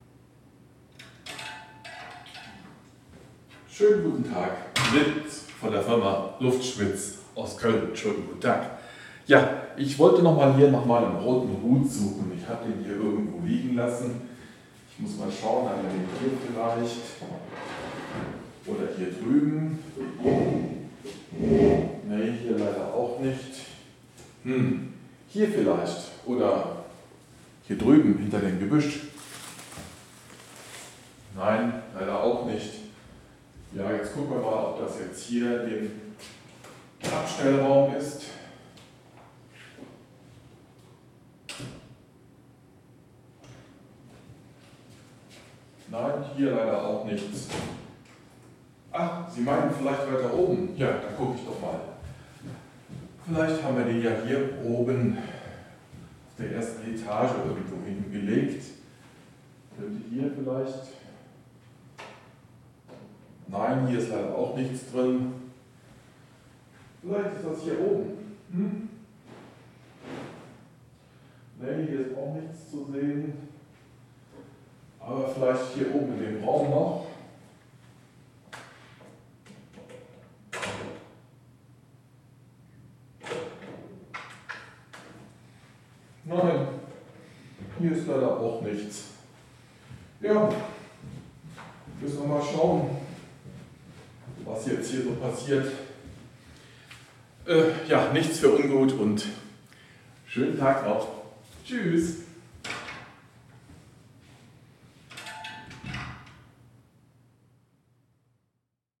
ORTF-3D Test 2.mp3